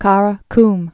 (kärə km)